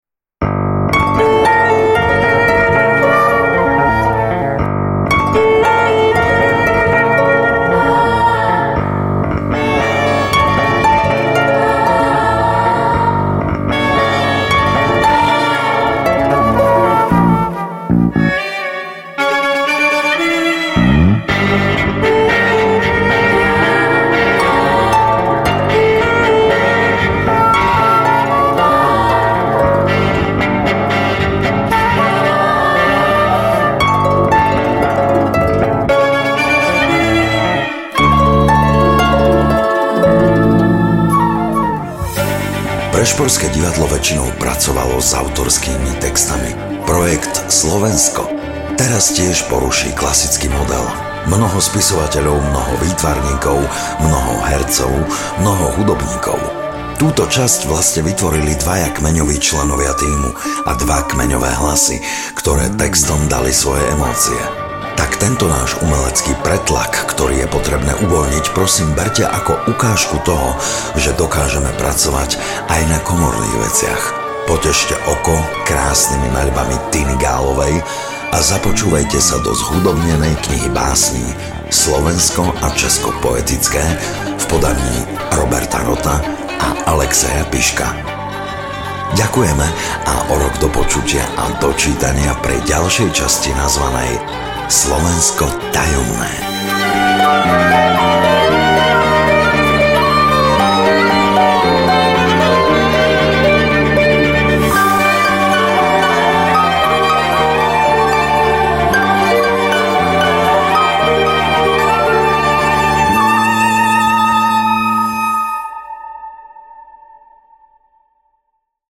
Slovensko Poetické audiokniha
Ukázka z knihy